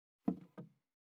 231,机に物を置く,テーブル等に物を置く,食器,グラス,コップ,工具,小物,雑貨,コトン,トン,ゴト,ポン,
コップ効果音室内物を置く